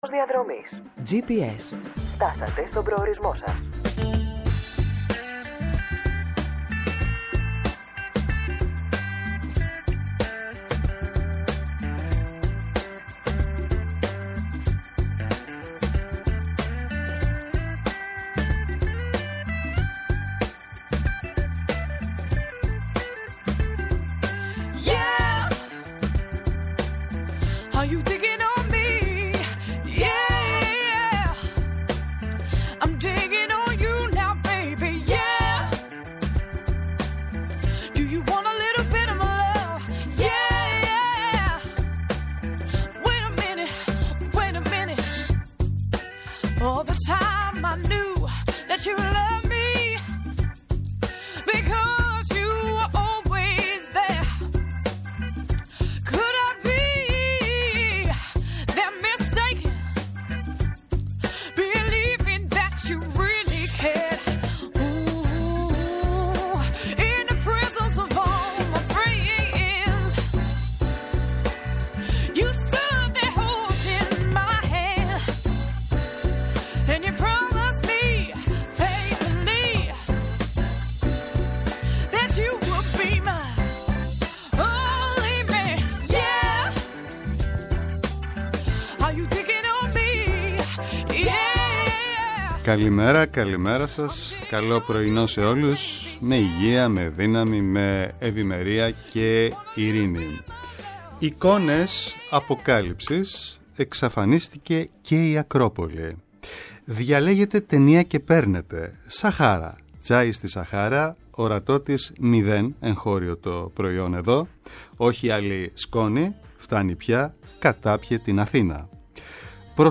-O Θανάσης Κοντογεώργης, υφυπουργός παρά τω Πρωθυπουργώ